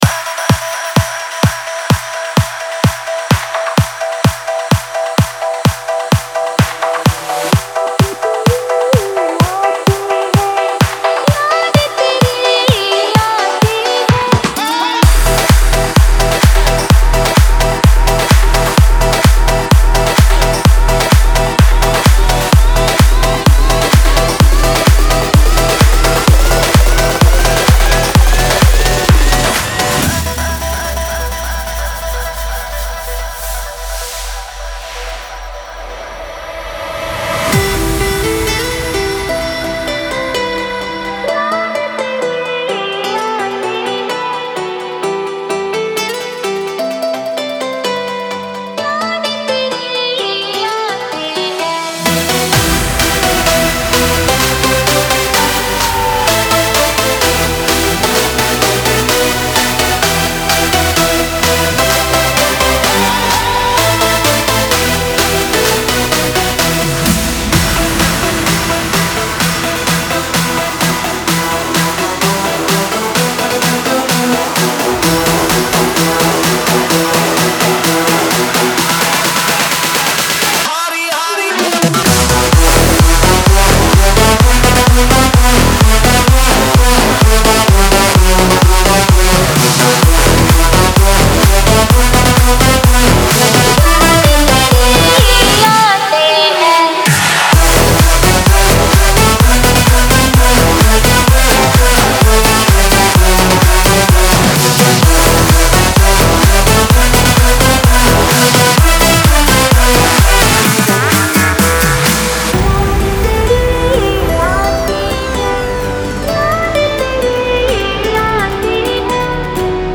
Стиль: Electro House / Electro / Dutch House